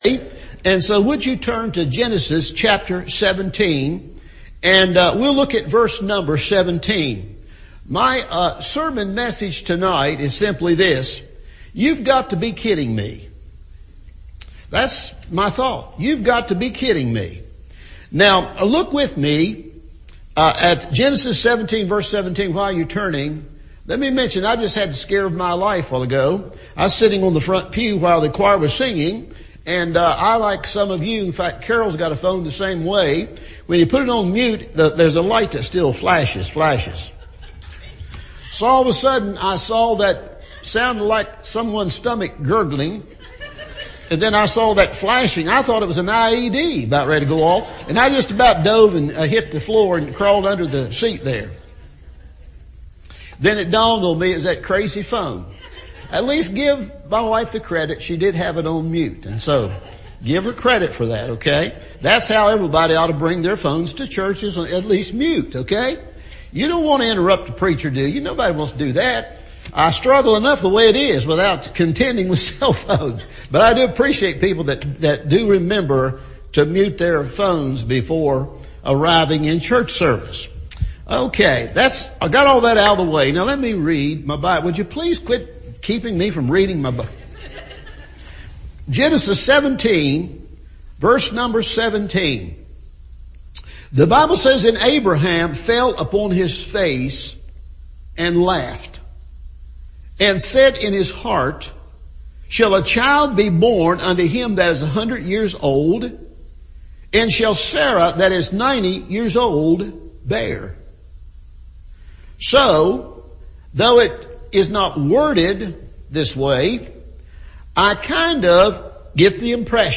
You’ve Got to Be Kidding Me! – Evening Service